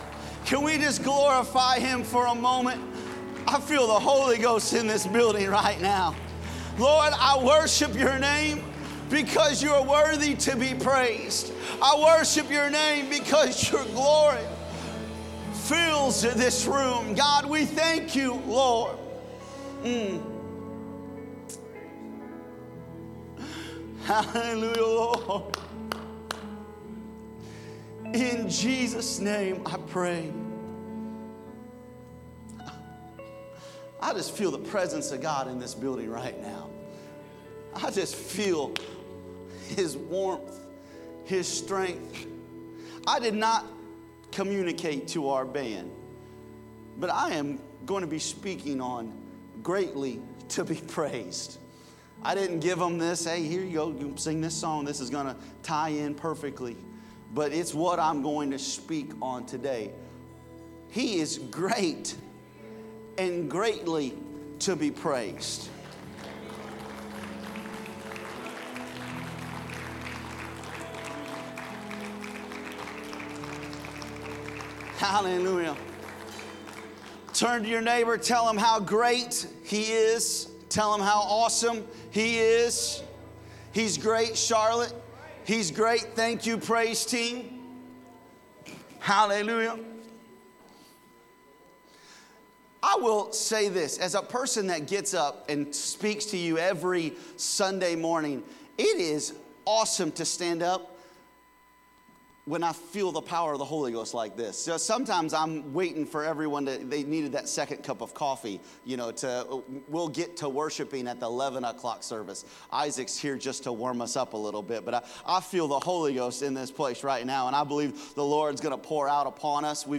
Sermons | Elkhart Life Church